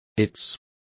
Complete with pronunciation of the translation of its.